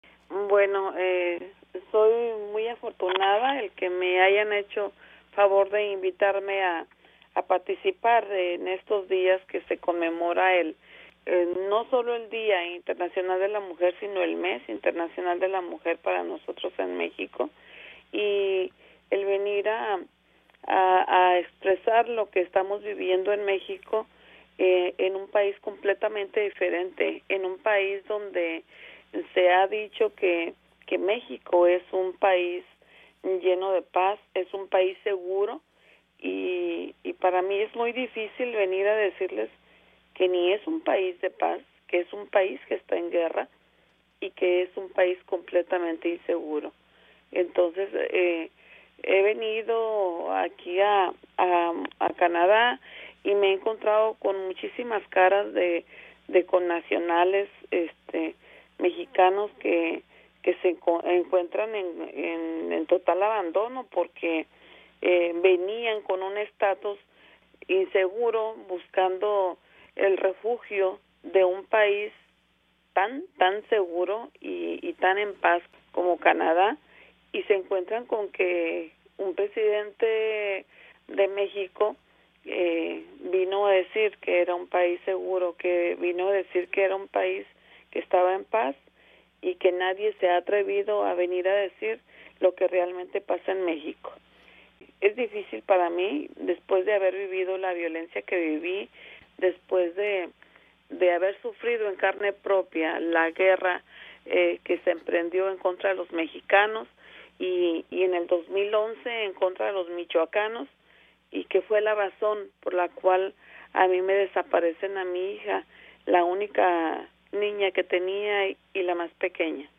Ella cuenta en entrevista con Radio Canadá Internacional la crónica de su desaparición.